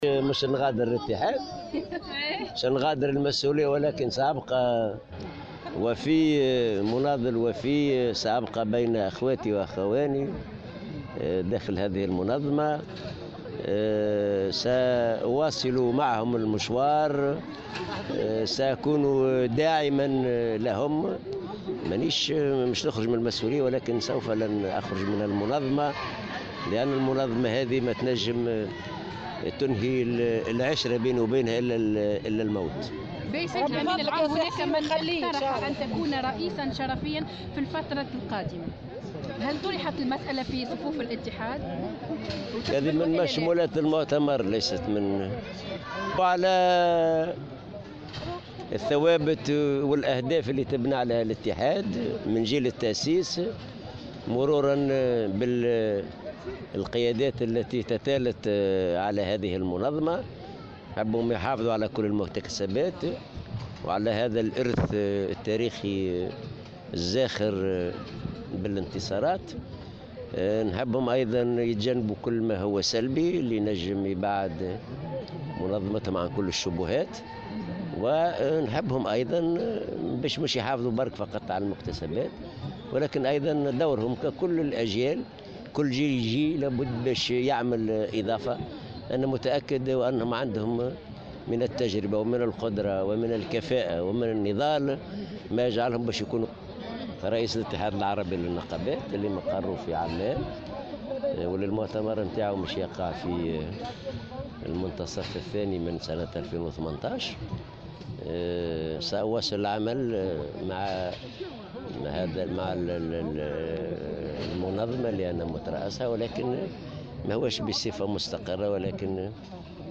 وأوضح في تصريحات صحفية على هامش المؤتمر الانتخابي للمنظمة الشغيلة أنه بعد الانتهاء من مسؤوليته التنفيذية سيبقى داعما للاتحاد وسيواصل النشاط صلب المنظمة النقابية.